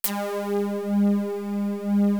JD SYNTHD2.wav